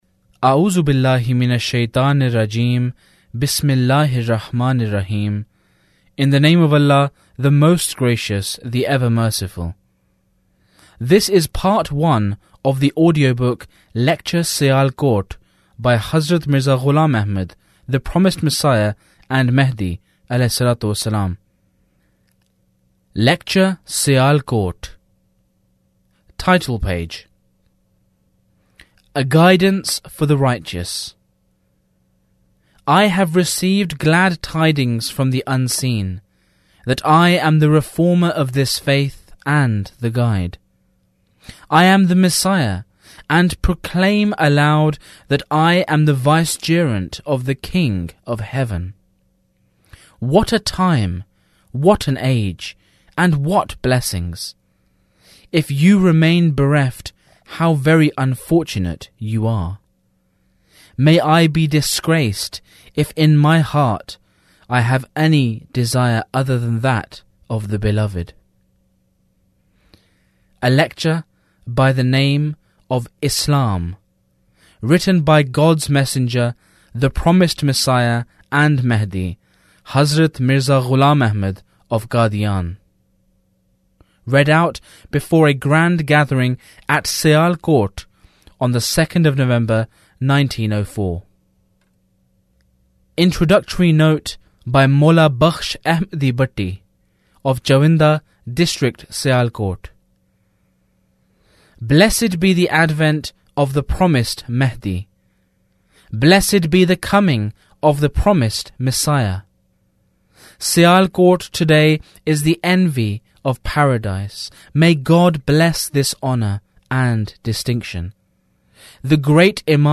Audiobook: Lecture Sialkot